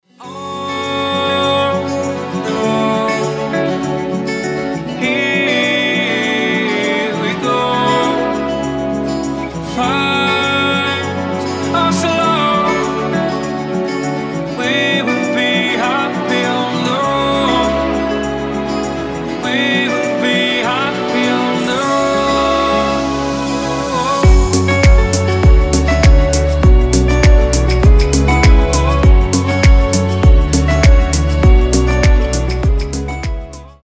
танцевальные
deep house , красивый мужской голос , красивая мелодия
гитара